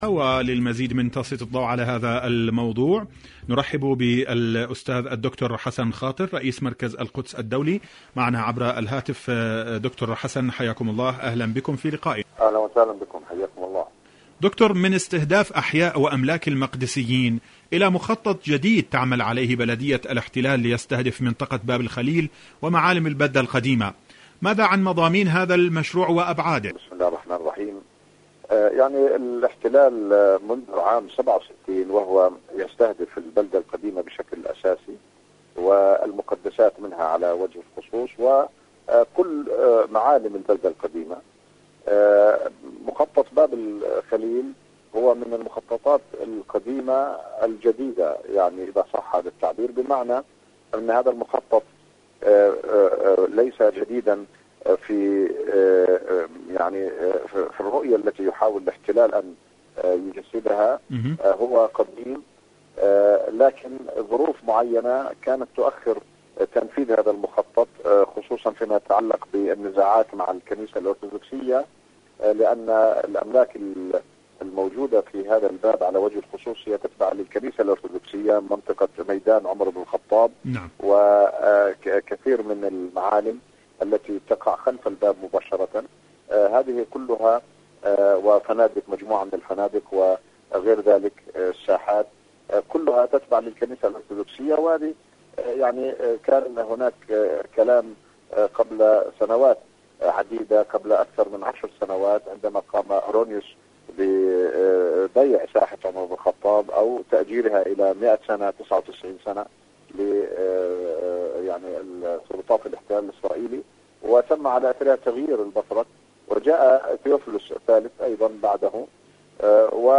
مقابلات برامج إذاعة طهران العربية برنامج فلسطين اليوم القدس الشريف كيان الاحتلال مقابلات إذاعية المسجد الأقصى الاحتلال معالم القدس استهداف معالم القدس شاركوا هذا الخبر مع أصدقائكم ذات صلة الكيان وأوراق الميدان..